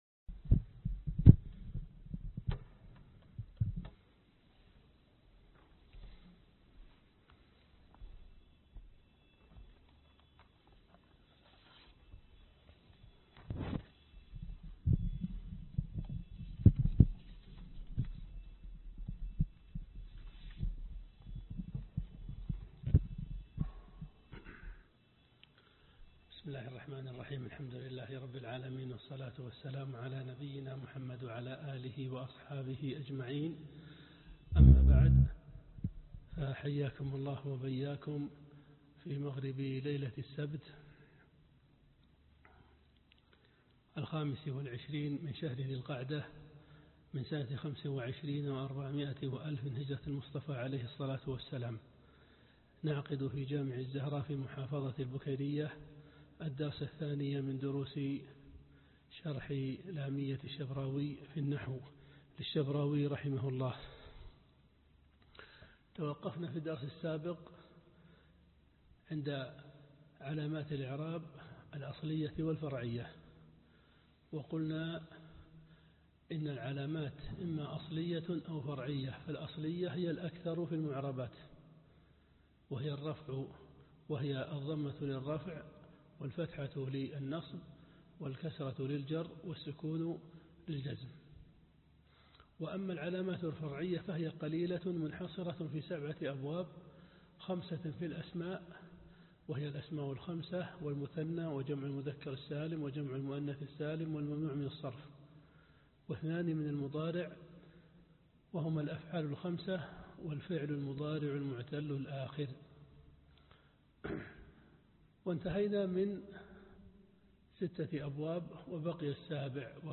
الدرس الثاني (شرح لامية الشبراوي في النحو)